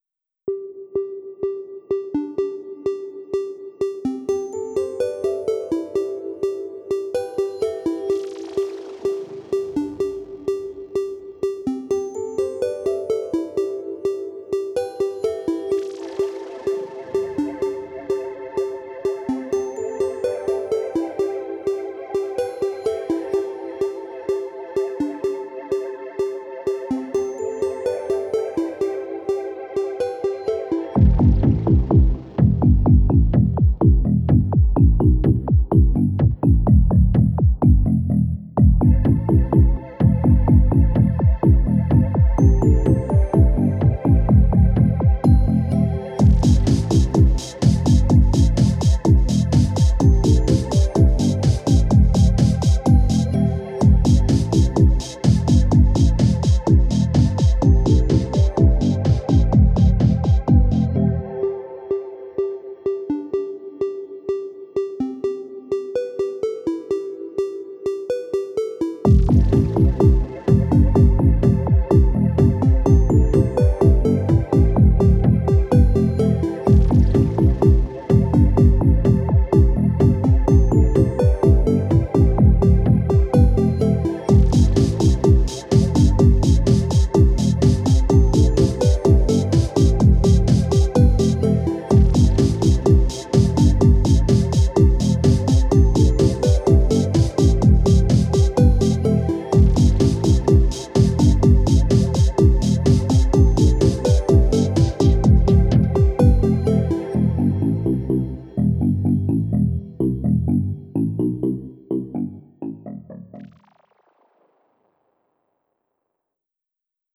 ELECTRO S-Z (34)